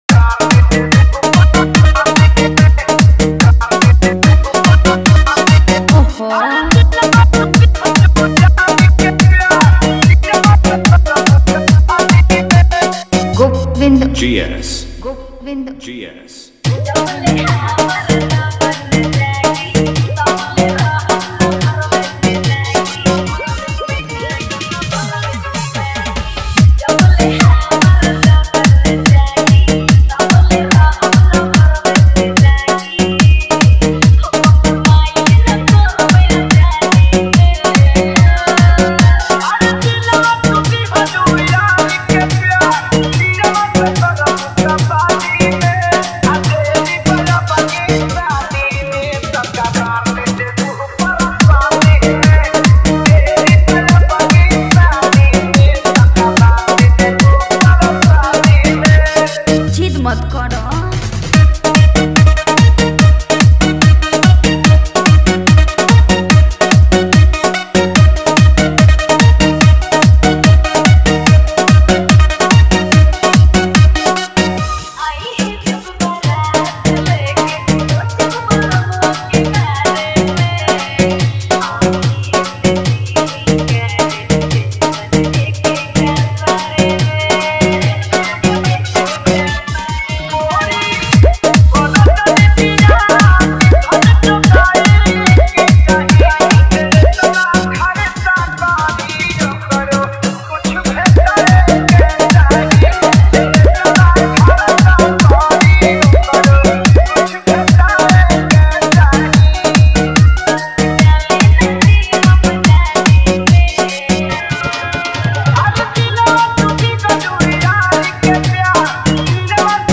1 EDM Remix
1 Dholki Remix Mp3 Song Free
New Bhojpuri Dj Remix Songs